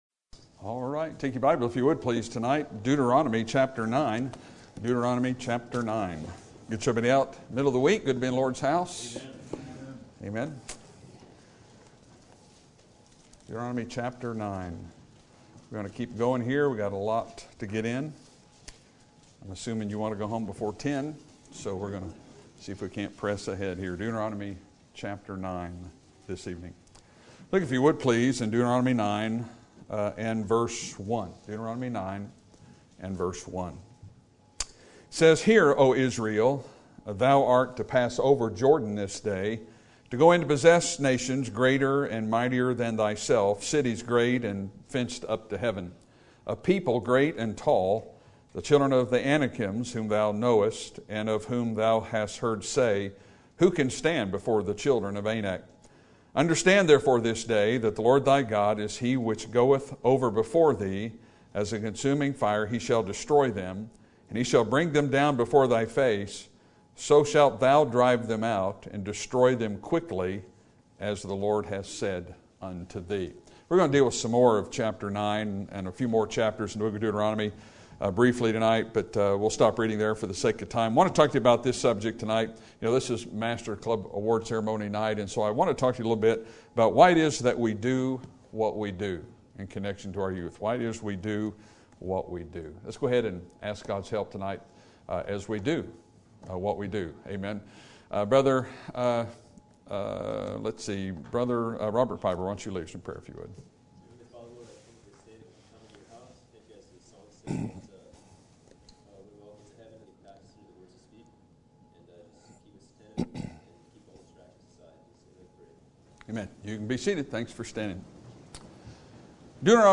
Sermon Date: Wednesday, June 26, 2019 - 7:00pm Sermon Title: Why Is It That We Do What We Do?